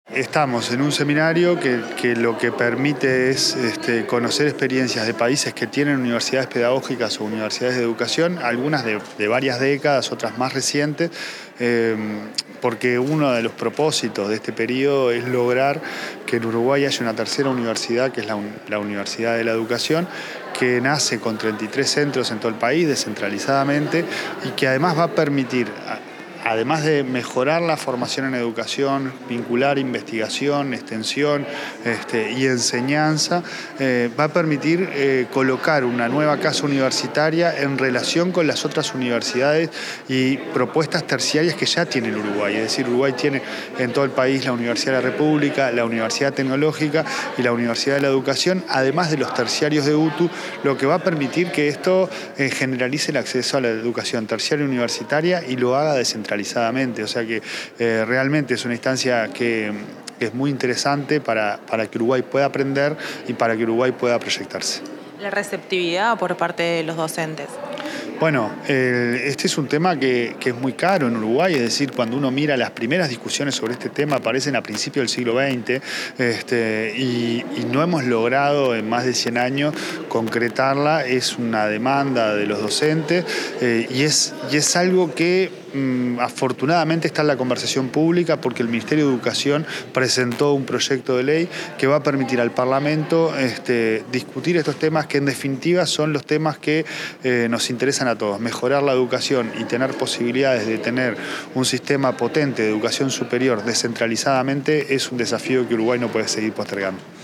Declaraciones del presidente de la ANEP, Pablo Caggiani